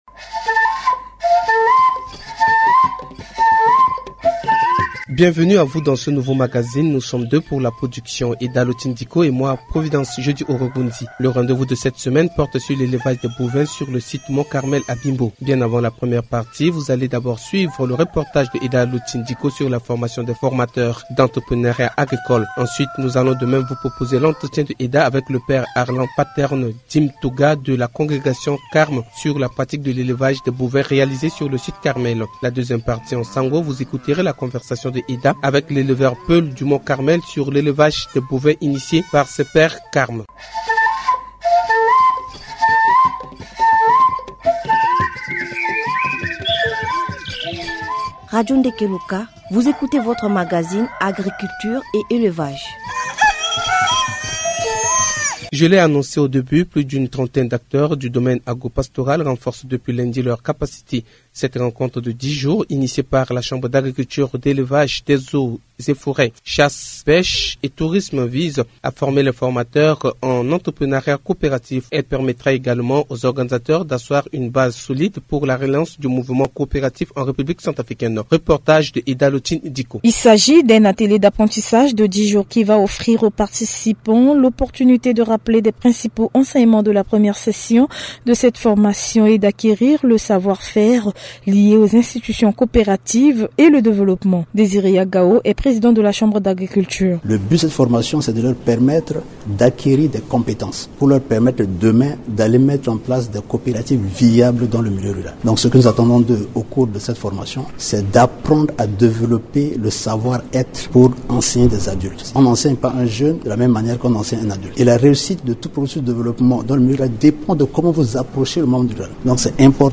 Initiative saluée par le gouvernement, cette émission encore une fois sur le site du Mont Carmel est une occasion d’échange avec les responsables.